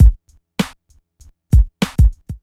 Beat 020-44S.wav